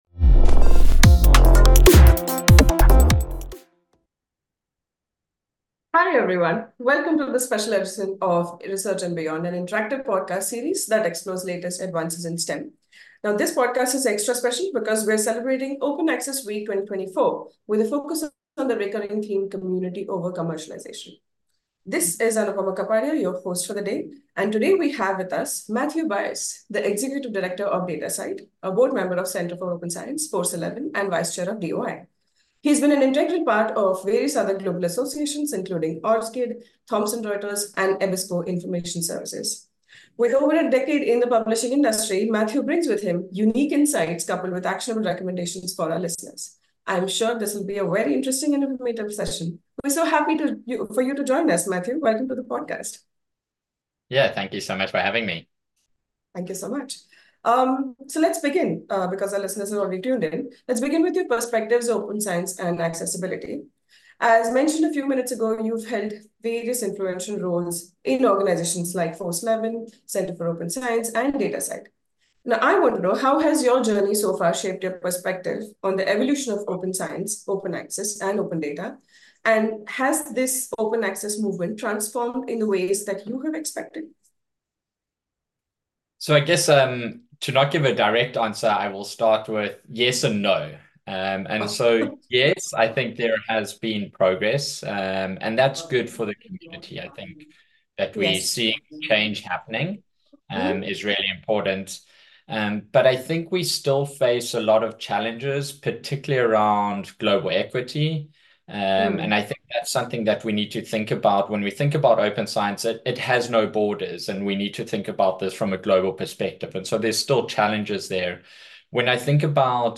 Gear up for an engaging discussion led by our Editor-in-chief and Communication Expert